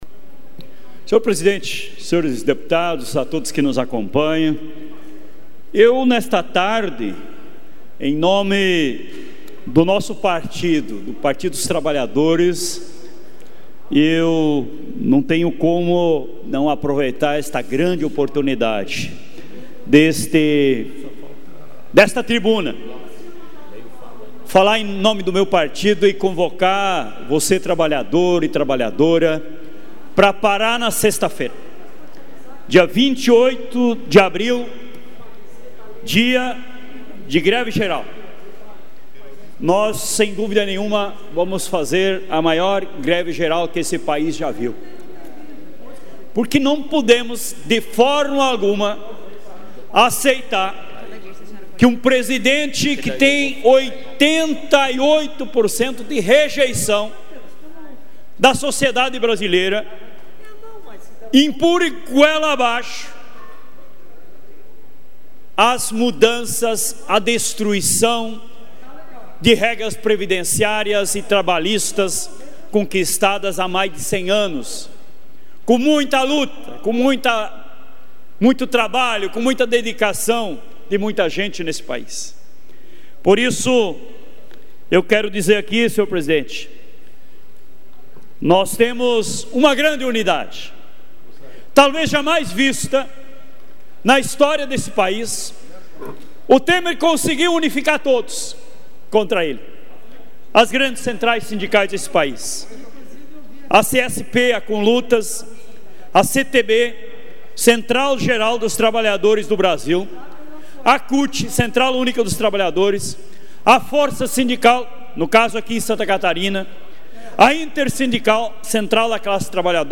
Confira aqui o pronunciamento dos deputados em tribuna, durante a sessão ordinária desta quarta-feira (26):